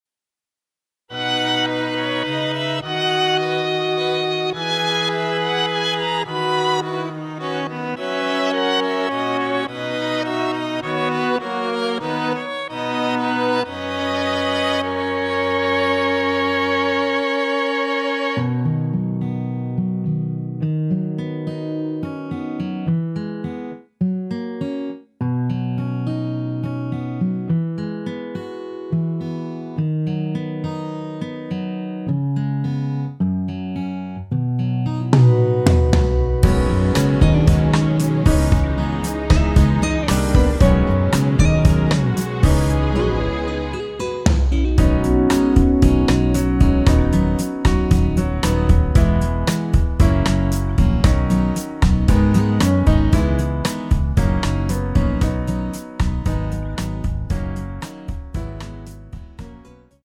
◈ 곡명 옆 (-1)은 반음 내림, (+1)은 반음 올림 입니다.
앞부분30초, 뒷부분30초씩 편집해서 올려 드리고 있습니다.
중간에 음이 끈어지고 다시 나오는 이유는
축가 MR